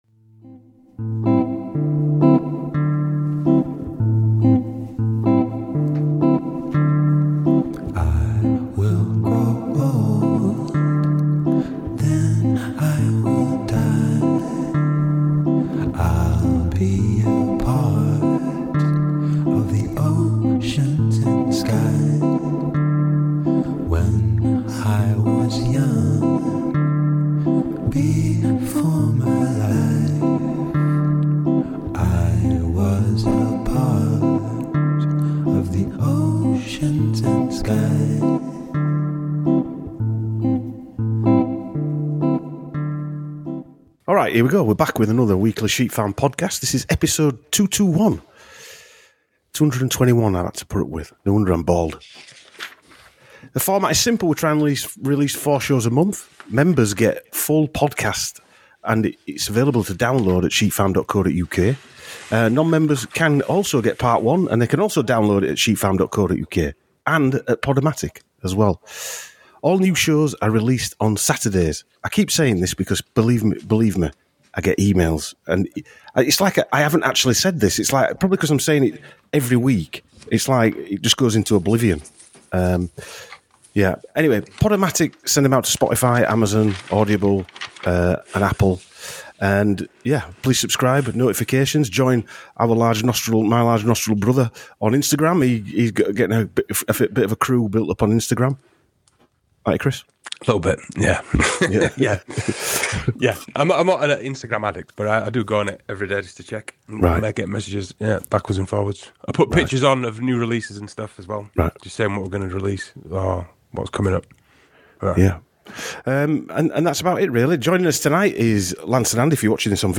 Chat